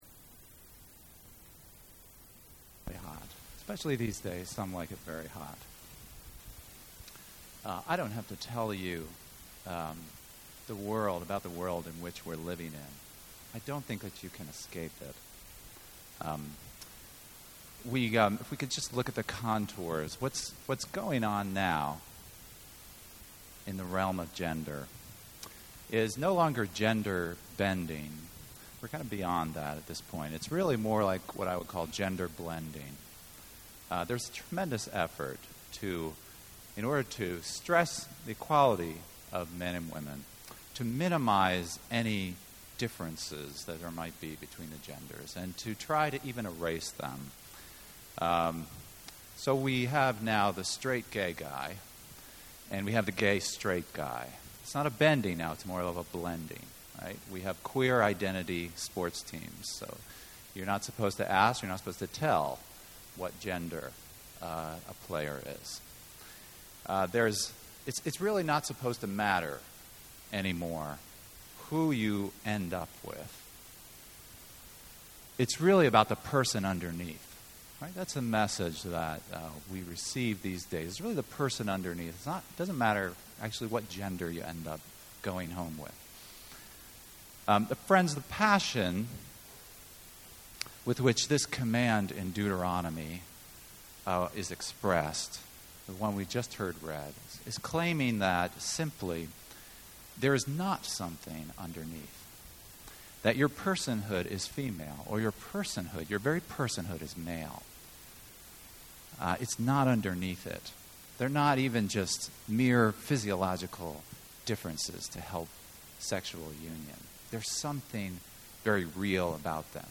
This turns out to be a ‘Part 1’ of a two-part message, this first part ending up applying to marriage.
The sports joke didn’t seem to land so well.